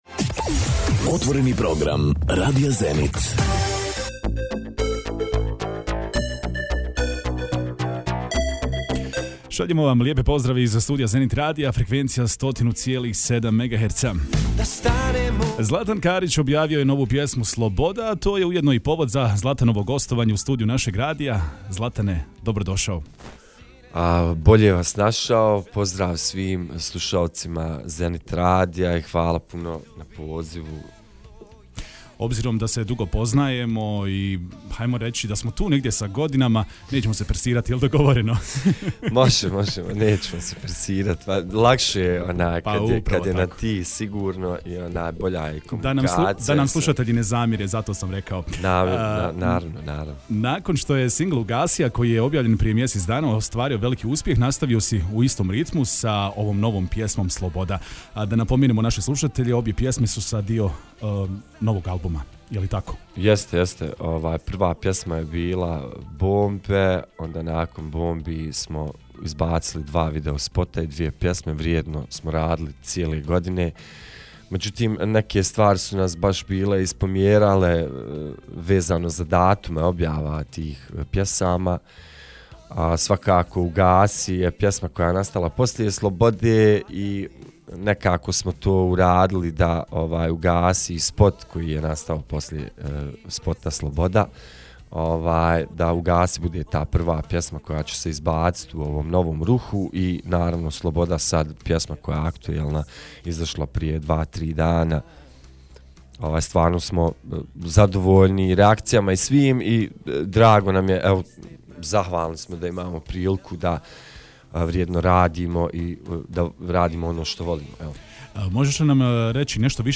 U nastavku poslušajte kompletan razgovor i poslušajte novu pjesmu. https